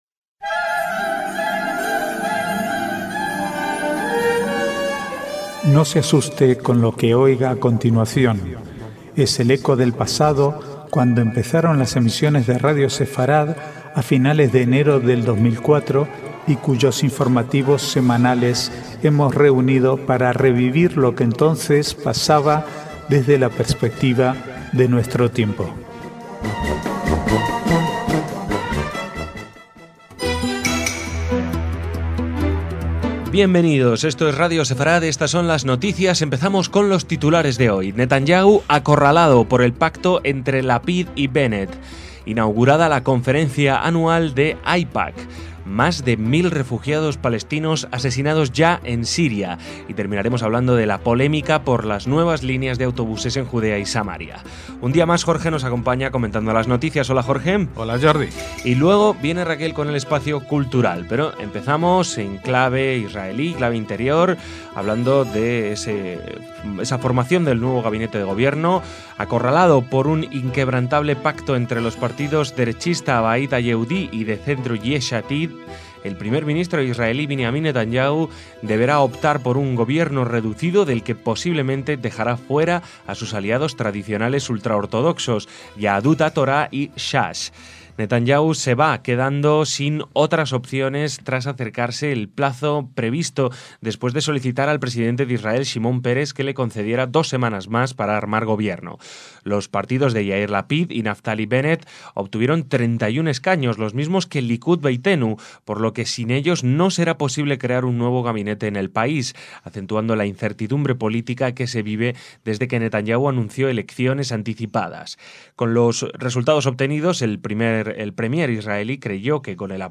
Archivo de noticias del 5 al 8/3/2013